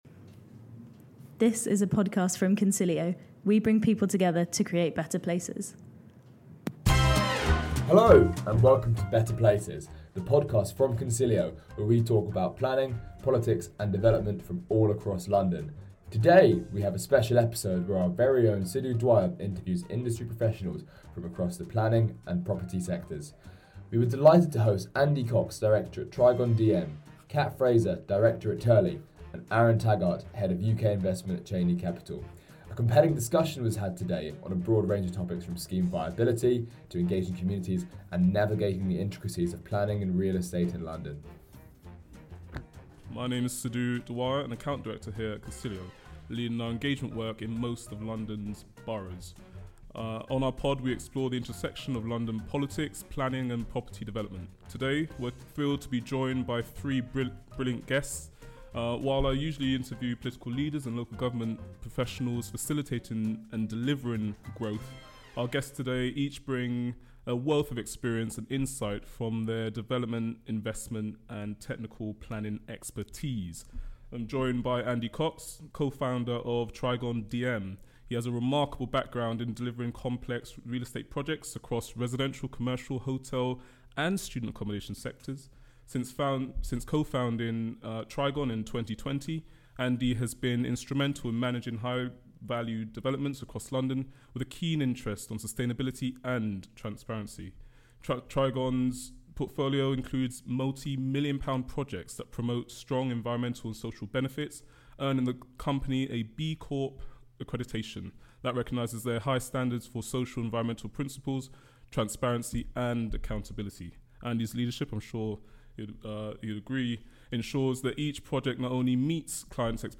On the latest edition of Better Places, we sat down with leading industry professionals across the property and planning sectors.